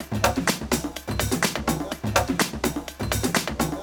C#m (D Flat Minor - 12A) Free sound effects and audio clips
• techno drum top loop voices.wav
techno_drum_top_loop_voices_F87.wav